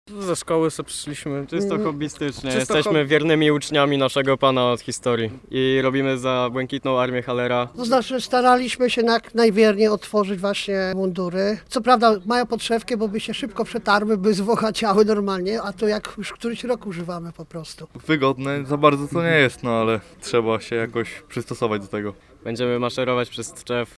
Posłuchaj relacji osób biorących udział w obchodach 105. Dnia Tczewa: